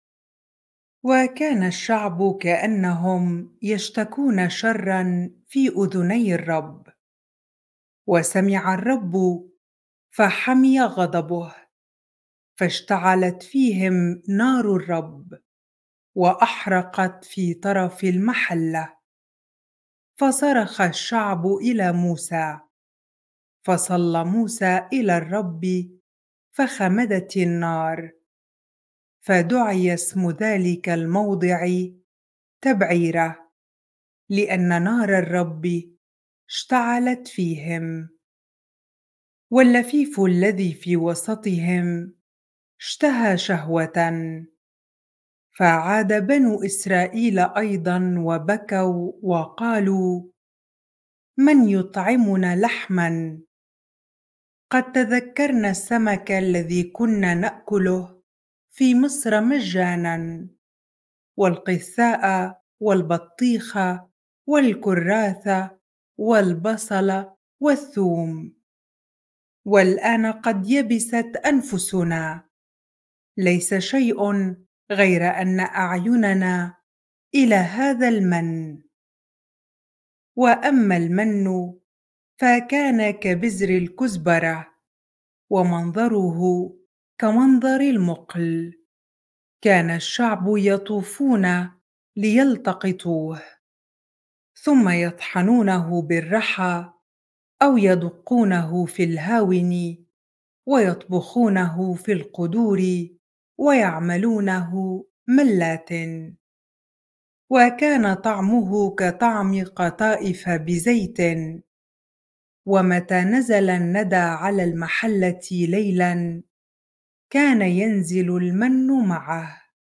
bible-reading-numbers 11 ar